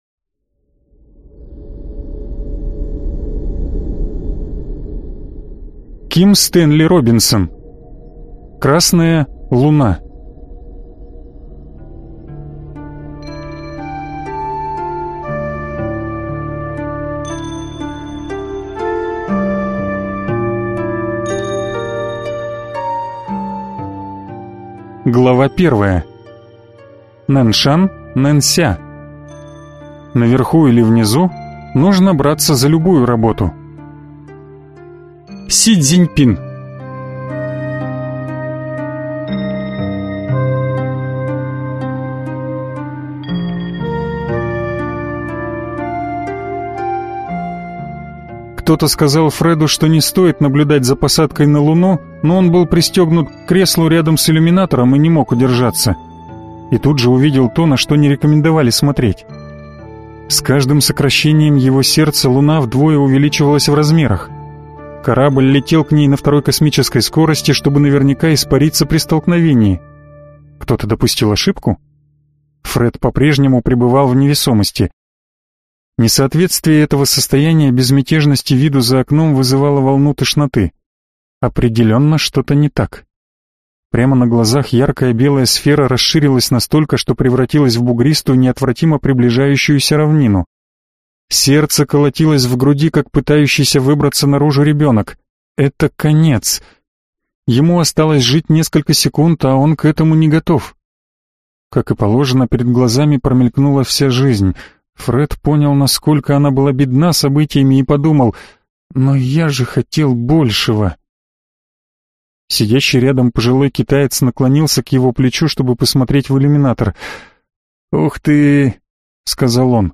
Аудиокнига Красная Луна - купить, скачать и слушать онлайн | КнигоПоиск
Прослушать фрагмент аудиокниги Красная Луна Ким Робинсон Произведений: 5 Скачать бесплатно книгу Скачать в MP3 Вы скачиваете фрагмент книги, предоставленный издательством